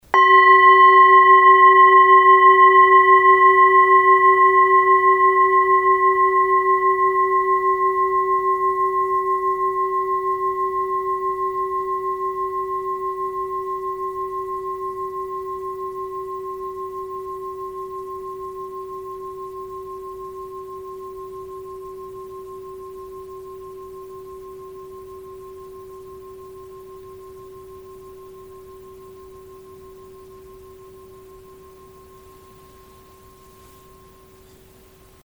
Struck or rubbed, the bowls produce a long-lasting, overtone-rich, and fine sound.
Sound sample Arhat singing bowl 500g:
Arhat-Klangschale-500g-Hoerprobe.mp3